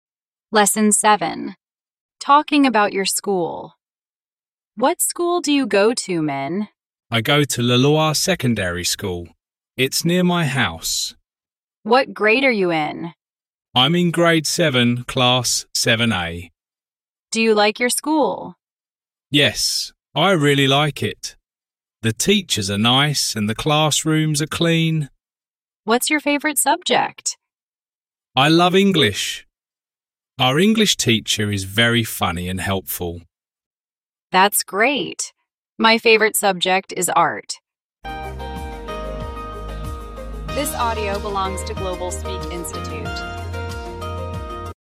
Giọng chậm